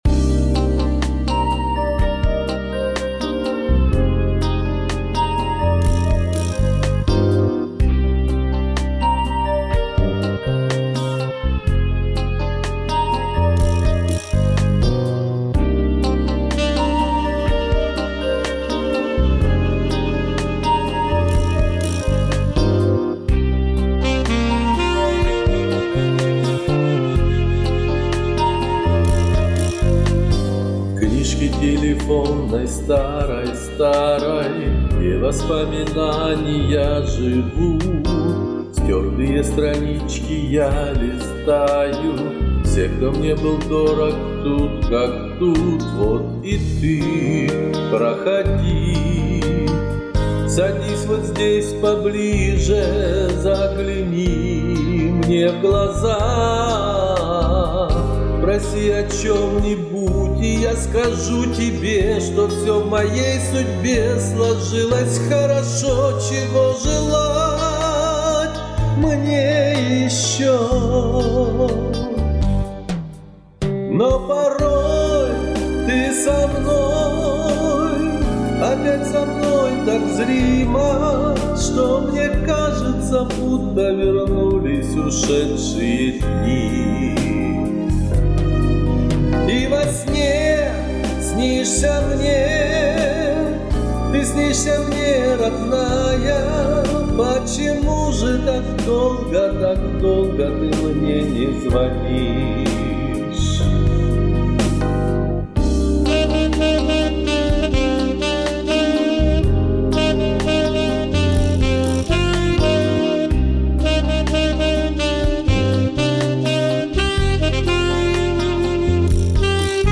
СПЕЛ ЕЕ СКАЖЕМ ПО-МУЖСКИ!